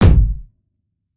Kick (10).wav